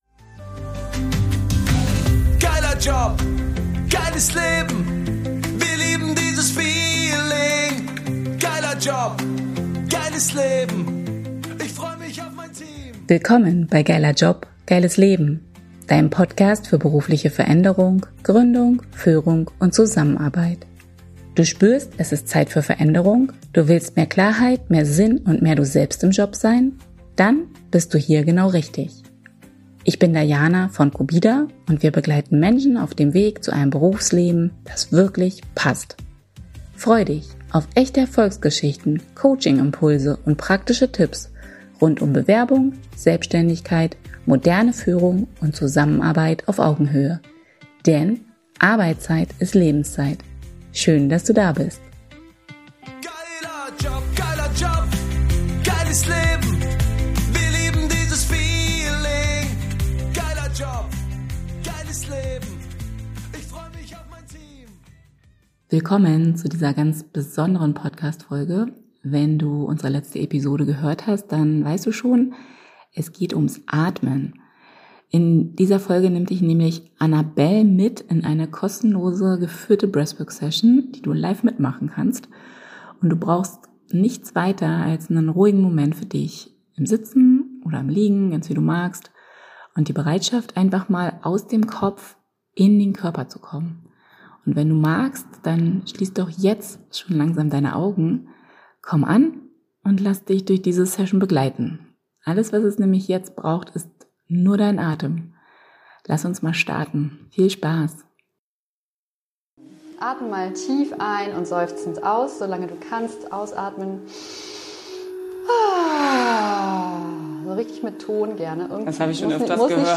In dieser Breathwork-Session starten wir mit bewusstem Seufzen / Ausatmen als Energy Release und gehen dann in eine Praxis, die dich Schritt für Schritt aus dem Kopf zurück in den Körper bringt.